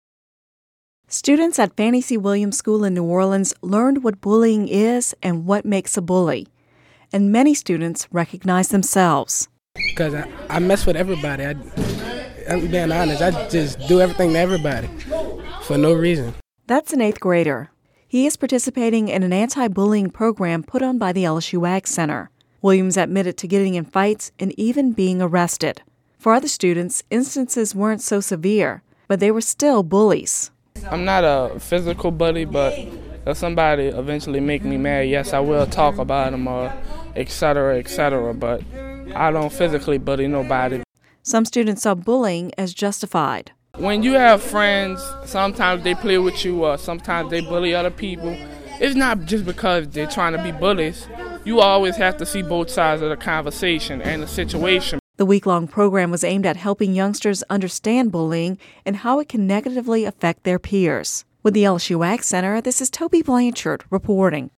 (Radio News 11/01/10) Students at Fannie C. Williams School in New Orleans recently learned what bullying is and what makes a bully, and during the process many students recognized they may be bullies. The students were participating in an anti-bullying program presented by the LSU AgCenter.